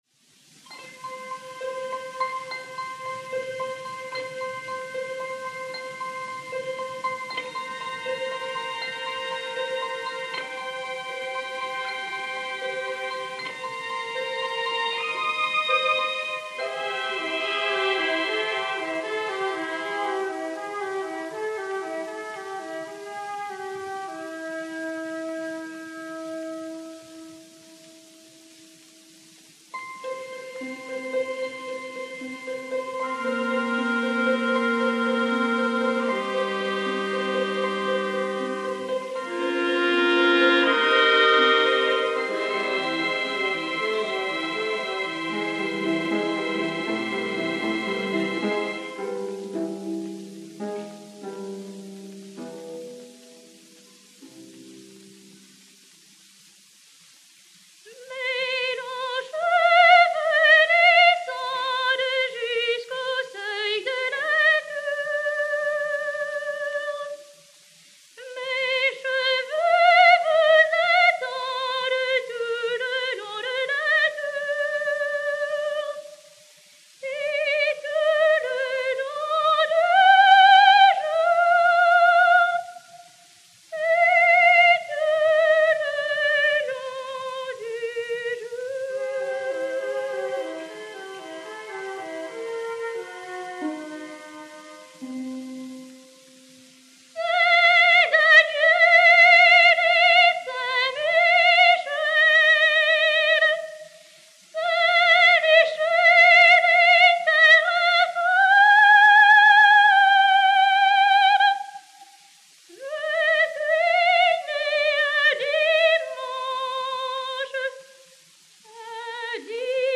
enr. � Paris en 1930